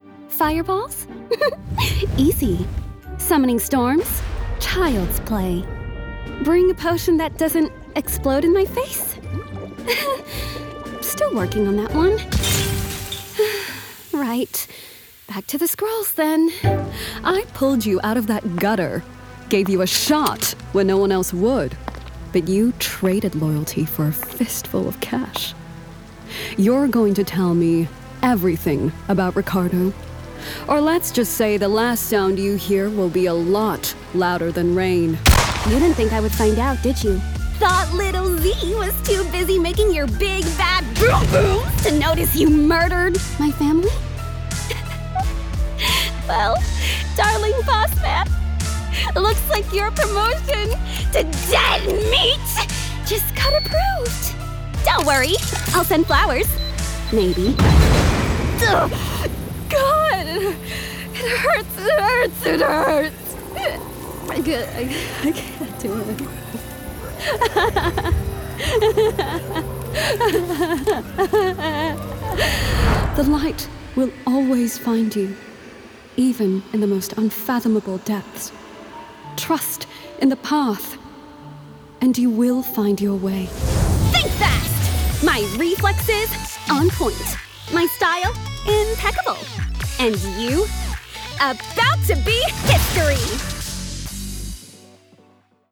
standard us | character
GAMING 🎮